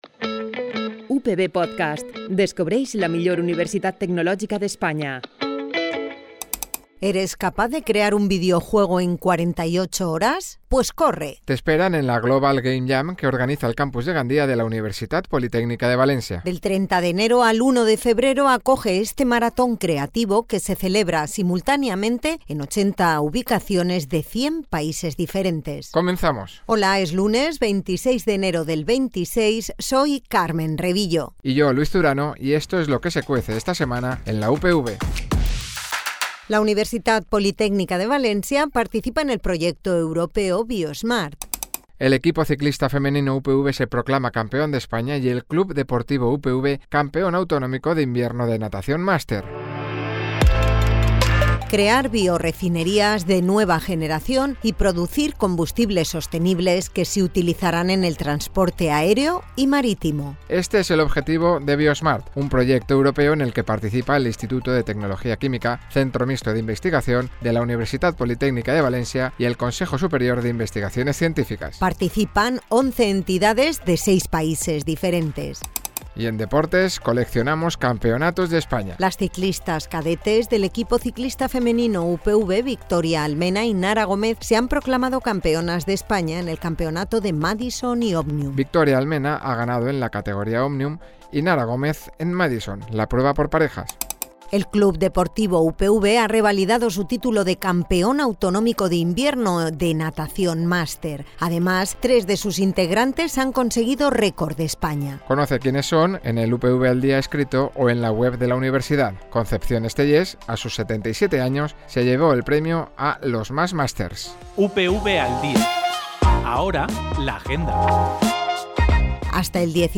Es la versión sonora del Boletín Informativo para informarte de lo que pasa en la Universitat Politècnica de València.